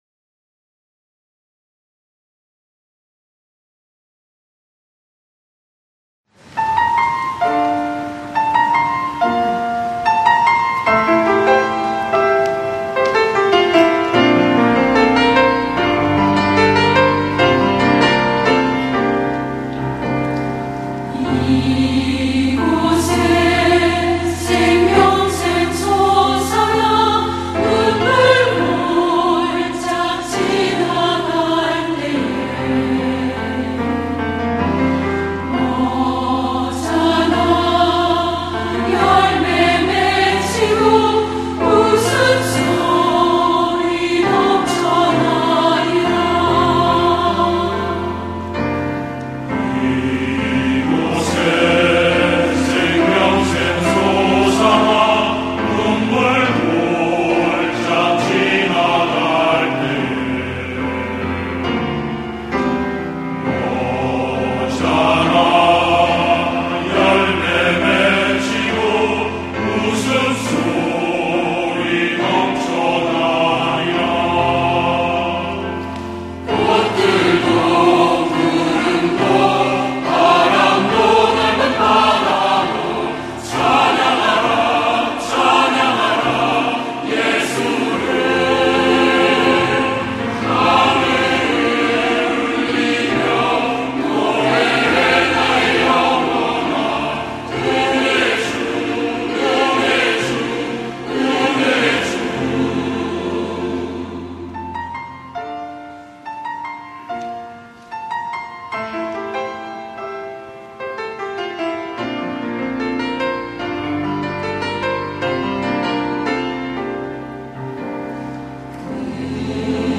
꽃들도 > 찬양영상